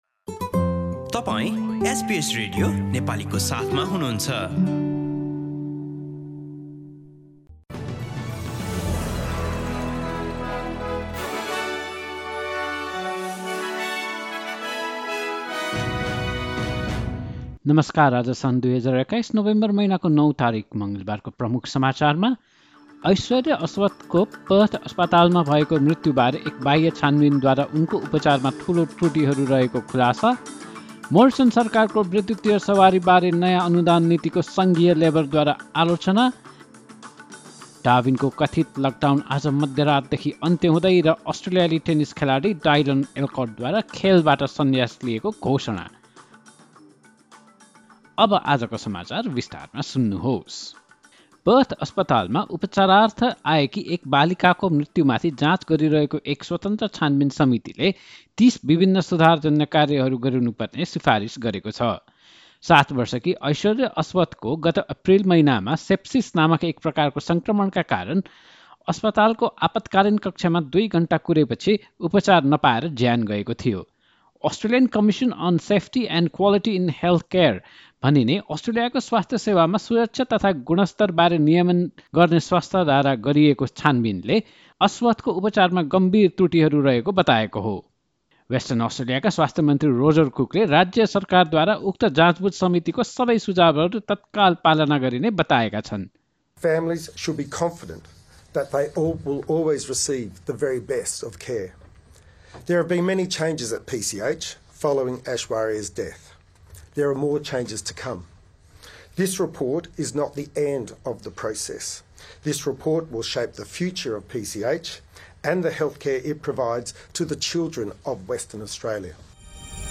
एसबीएस नेपाली अस्ट्रेलिया समाचार: मङ्गलवार ९ नोभेम्बर २०२१